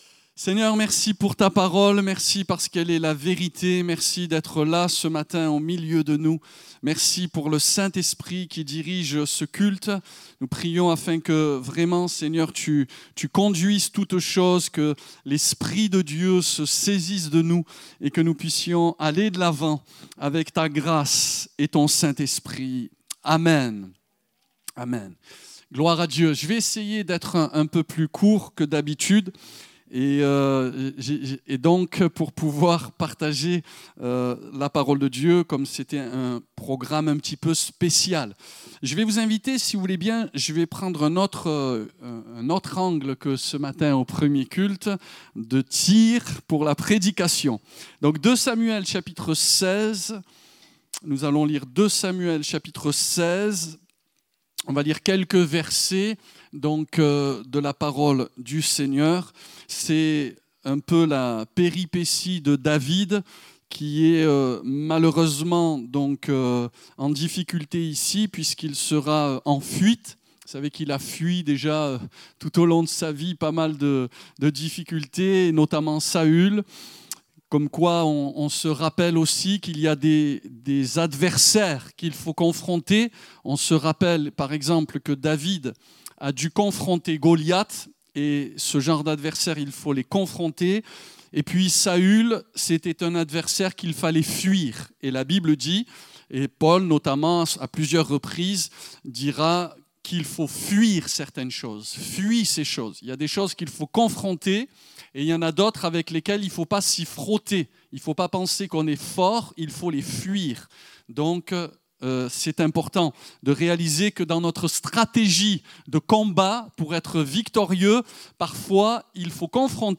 Découvrez en replay vidéo le message apporté à l'Eglise Ciel Ouvert